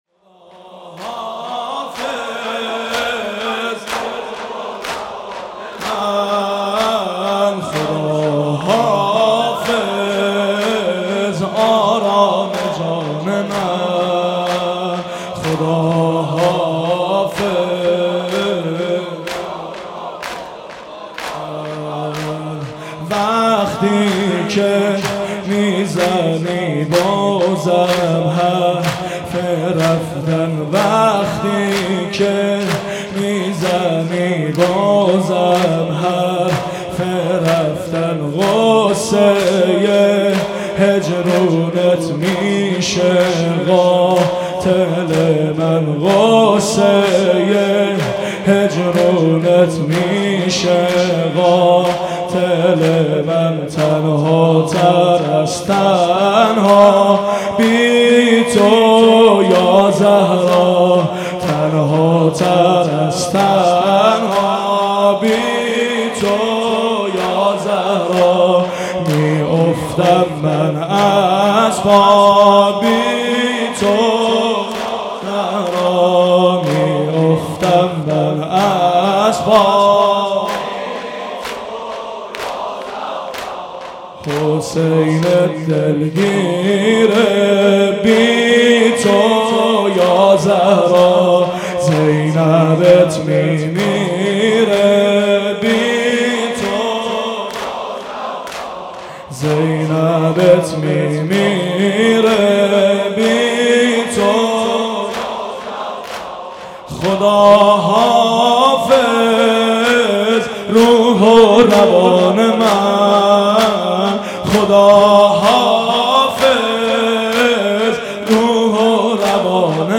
• حاج ابوذر بیوکافی/ شب چهارم فاطمیه 92
• حاج ابوذر, مداحی بیوکافی, زمینه فاطمیه, بیوکافی, حاج ابوذر بیوکافی, مداحی فاطمیه, فاطمیه بیوکافی